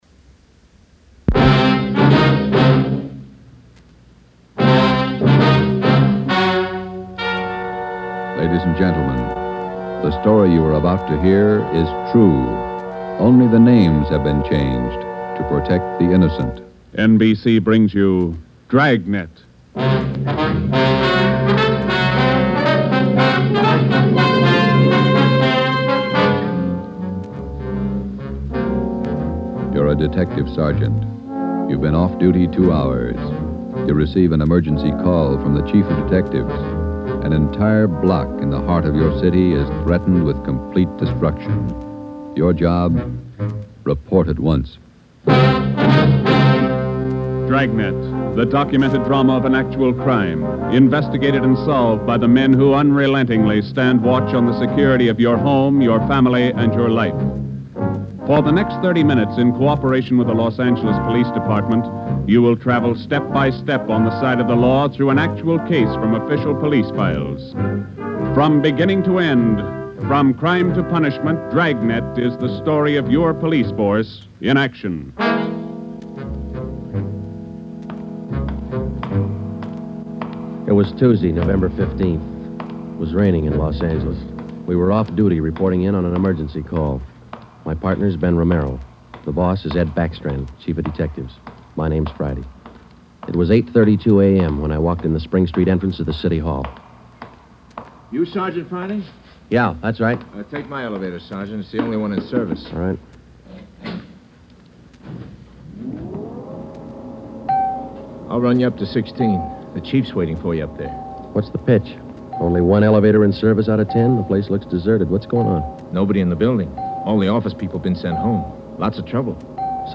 Dragnet is perhaps the most famous and influential police procedural drama in media history.